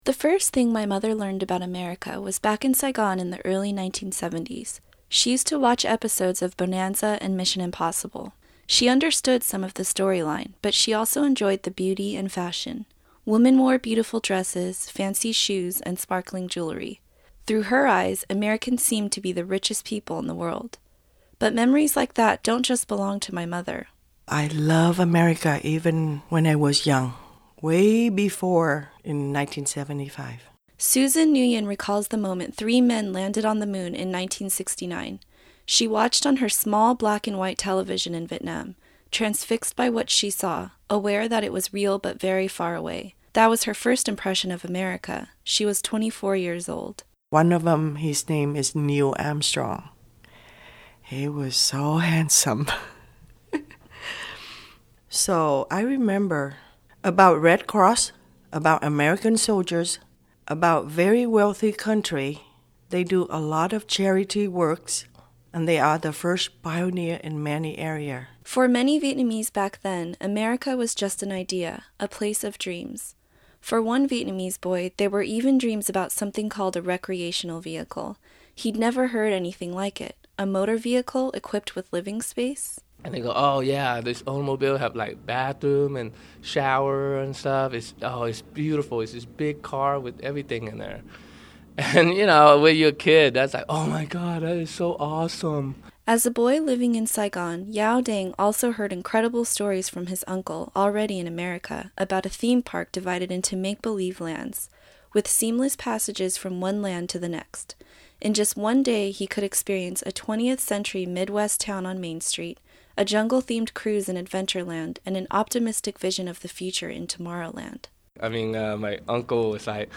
This year, she sat down with three Vietnamese-Americans old enough to remember their first impressions of a new country.